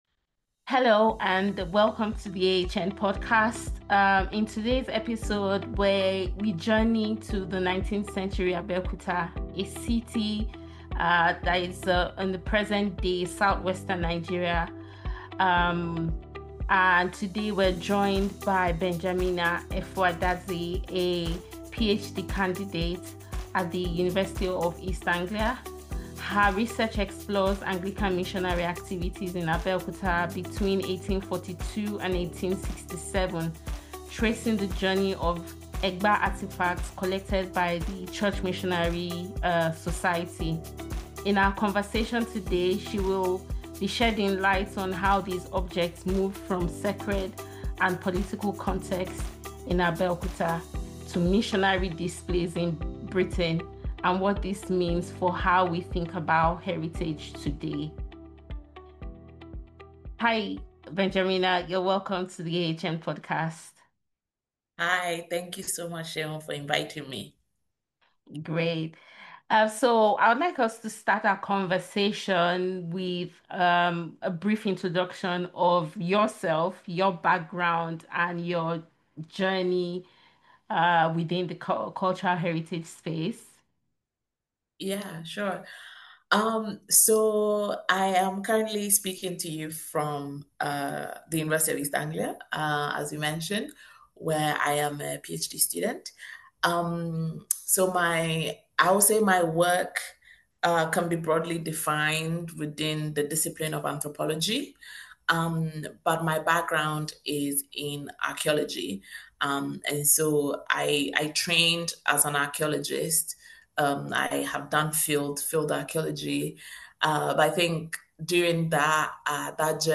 Importantly, this conversation reminds us that provenance research is not simply about where an object comes from, but about recovering the voices and intentions of those often erased from the archive.